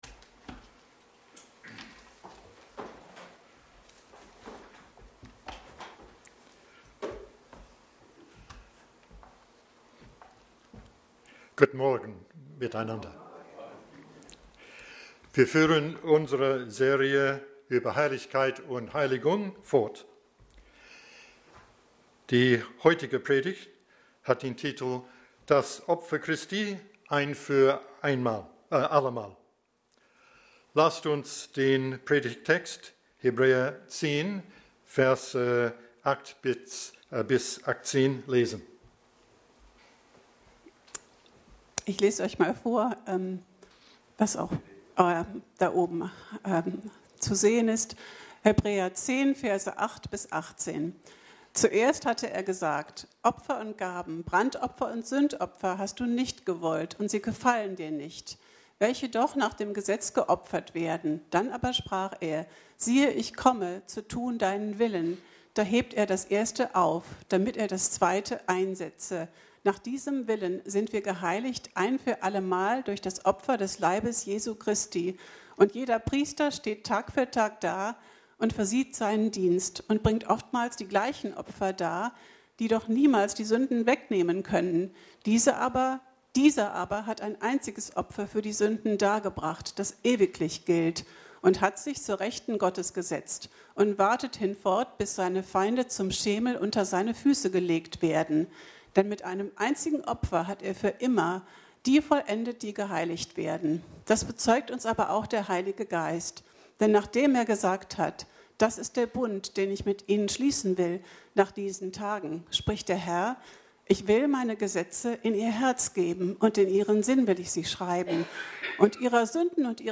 Passage: Hebräer 10,8-18 Dienstart: Predigt